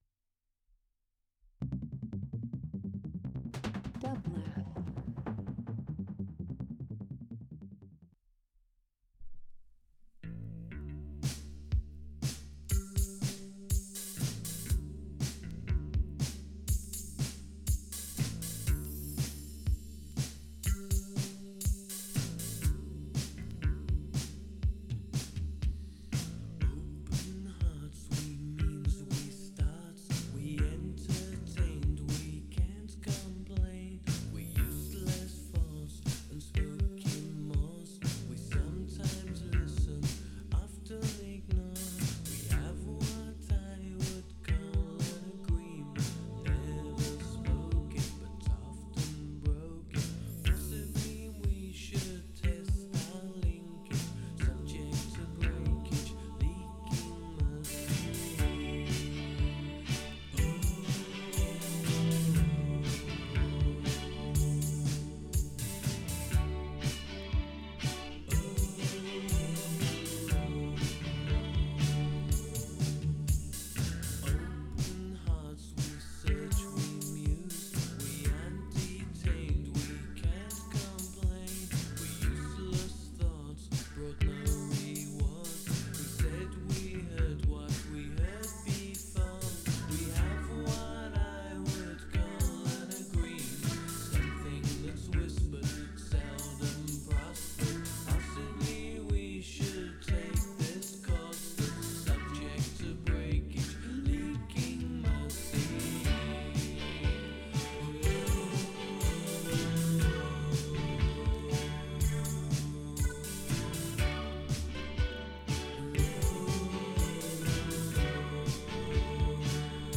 Alternative Dance Indie Techno